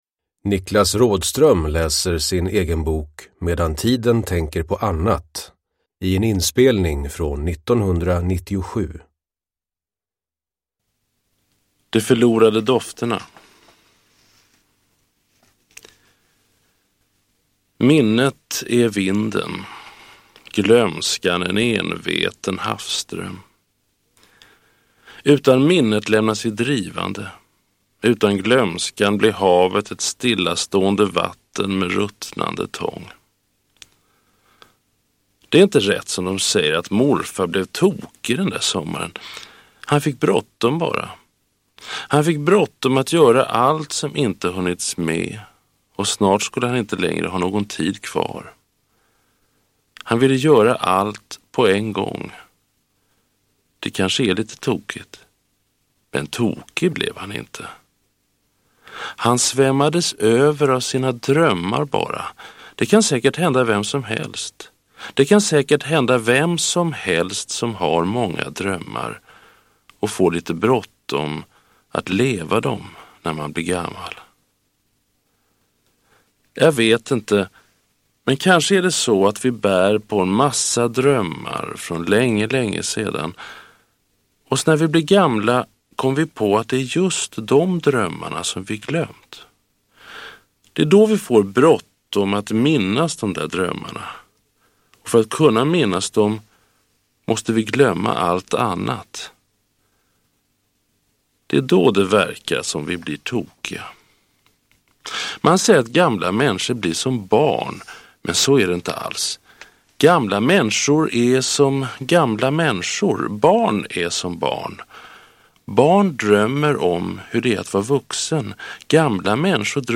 Produkttyp: Digitala böcker
Uppläsare: Niklas Rådström